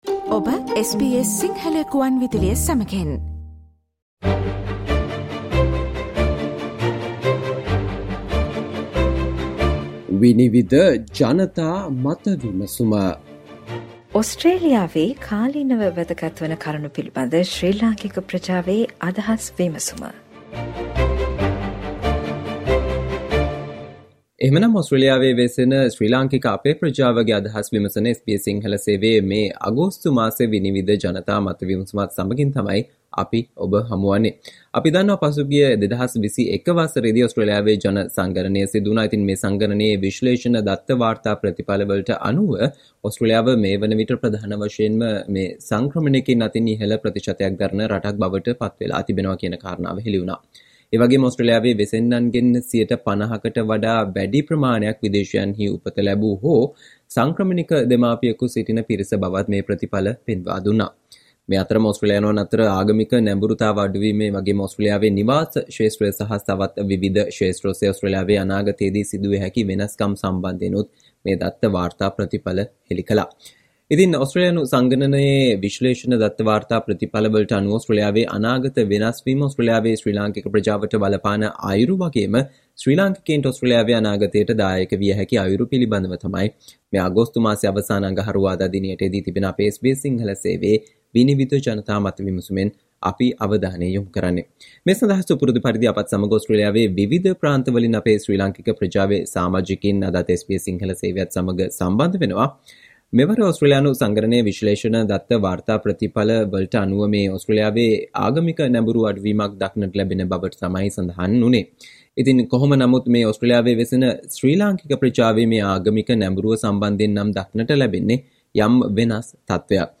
Panel discussion consists with the following invitees